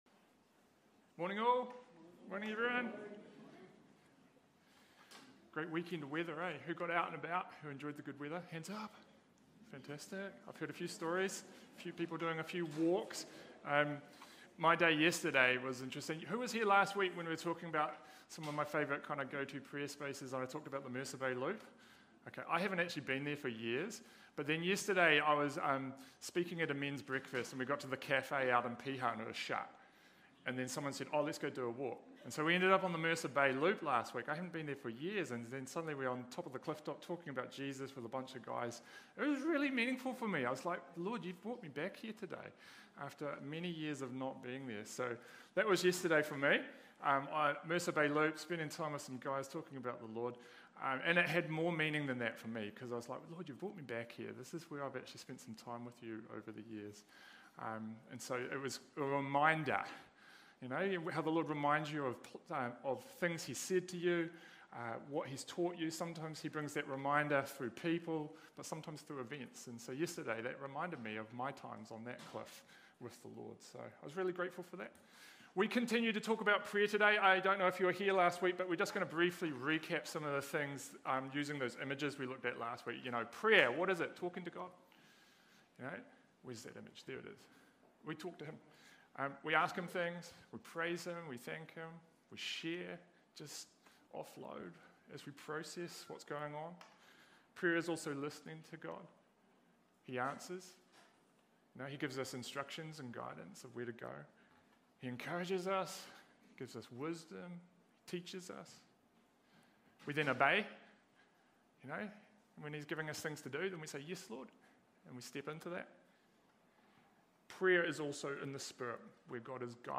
Sermons | Titirangi Baptist Church